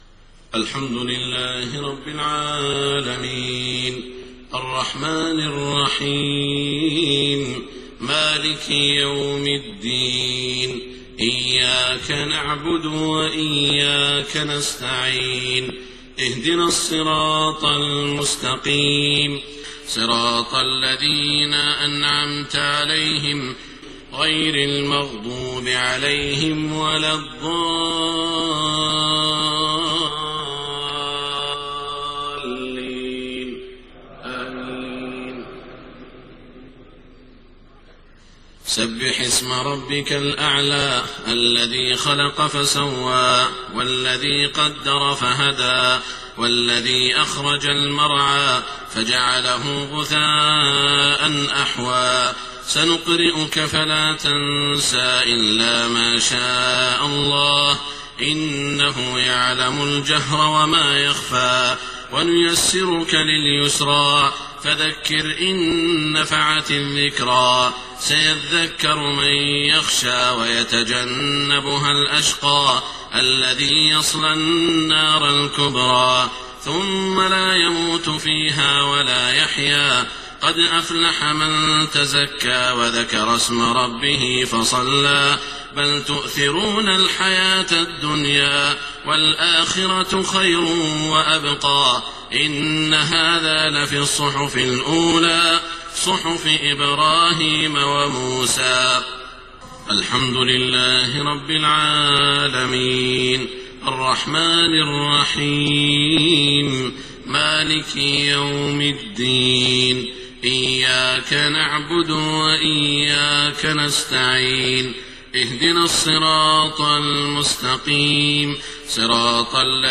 صلاة الجمعة 9 رجب 1429هـ سورتي الأعلى و الغاشية > 1429 🕋 > الفروض - تلاوات الحرمين